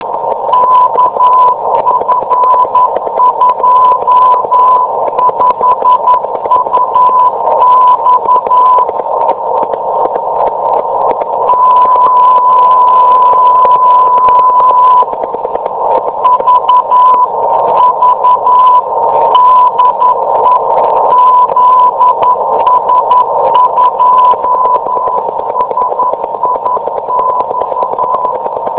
ZS2SIX at 50.005 MHz are copied at my QTH (the antenna heading for Africa almost coincides with that for the beacon).